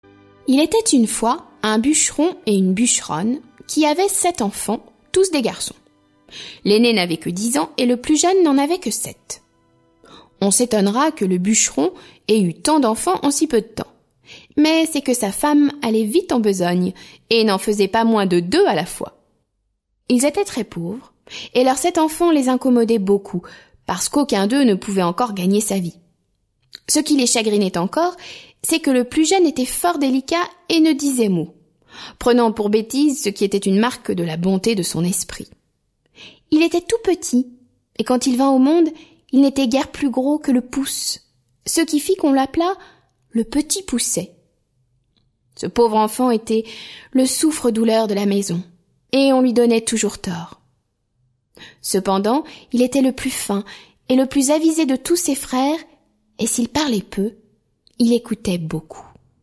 Conte de Charles Perrault Musique : Delibes (Coppelia, prélude) et Berlioz (Symphonie fantastique) Comédien